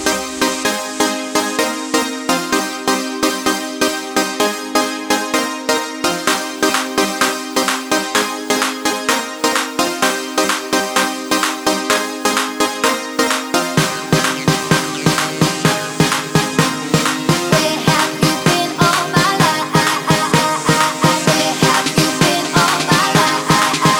R'n'B / Hip Hop